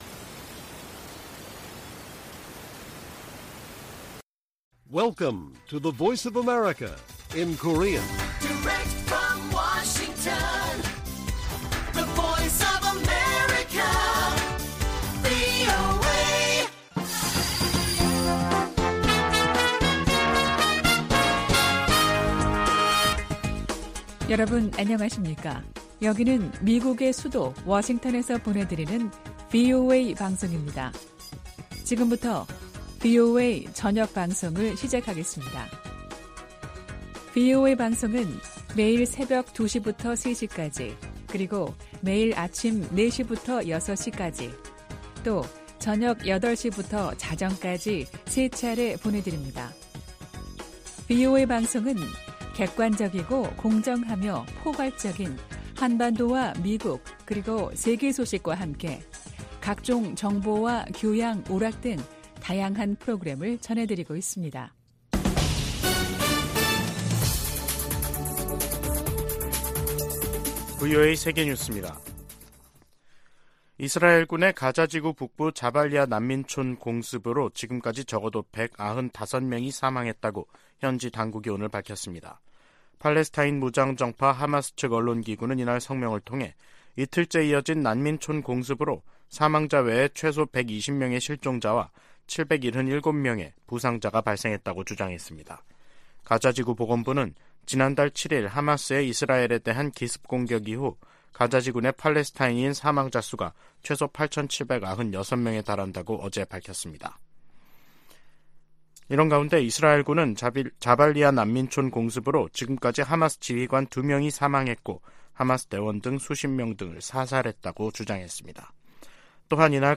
VOA 한국어 간판 뉴스 프로그램 '뉴스 투데이', 2023년 11월 2일 1부 방송입니다. 미 국방정보국장이 북한-이란-러시아 연계를 정보 당국의 주시 대상으로 지목했습니다. 조 바이든 미국 대통령이 공석이던 국무부 부장관에 커트 캠벨 백악관 국가안보회의 인도태평양 조정관을 공식 지명했습니다. 북한의 최근 잇따른 대사관 폐쇄는 국제사회의 제재가 작동하고 있다는 증거라고 미국 전직 외교관들이 분석했습니다.